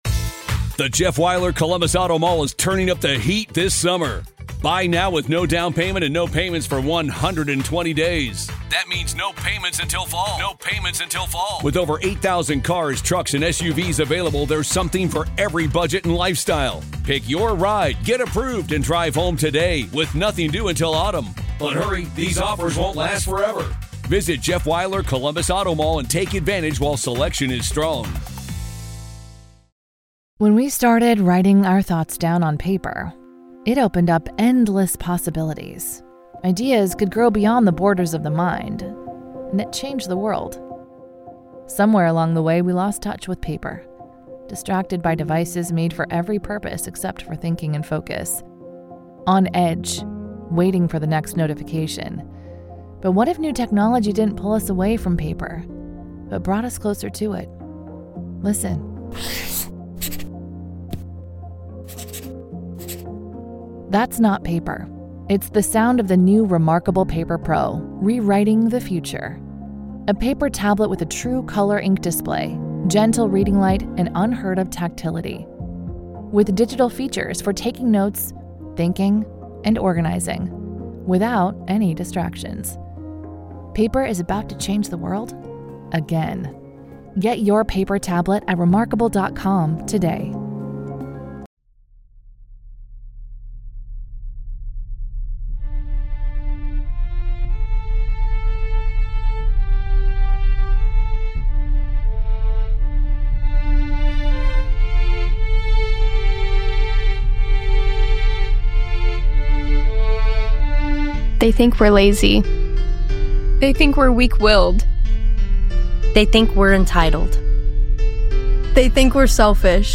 This episode is a reading